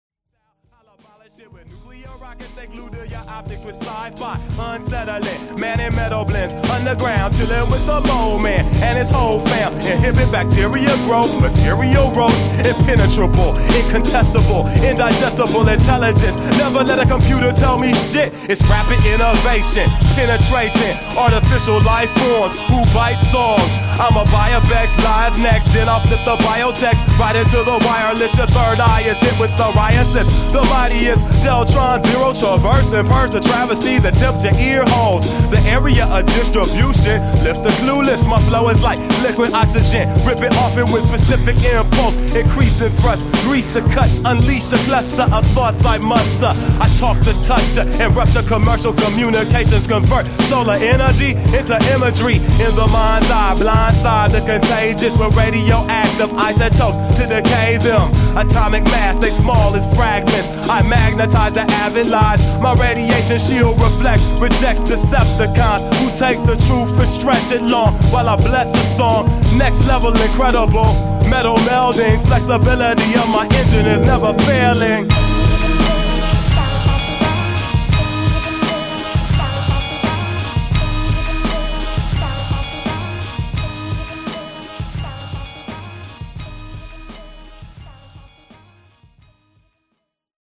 lacing scratches and vocal samples throughout every song